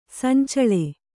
♪ sancaḷe